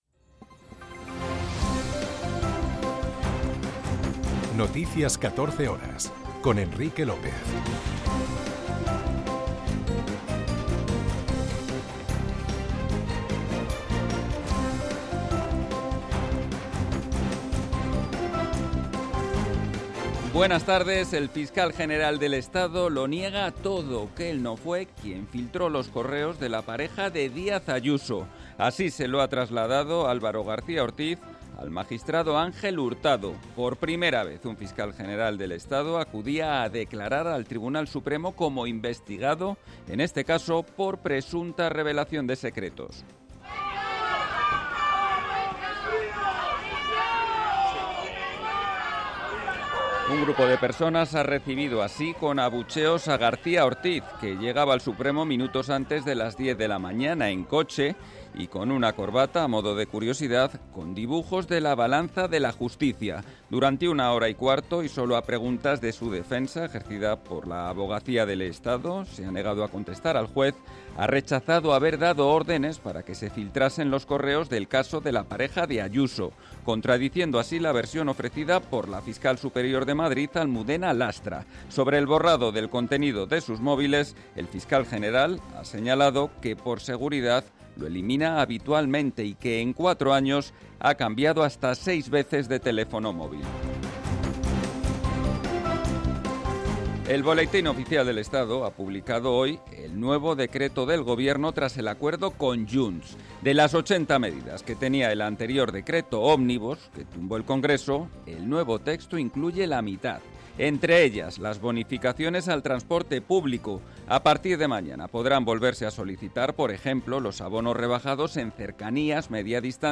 en España y en el mundo. 60 minutos de información diaria con los protagonistas del día y conexiones en directo en los puntos que a esa hora son noticia.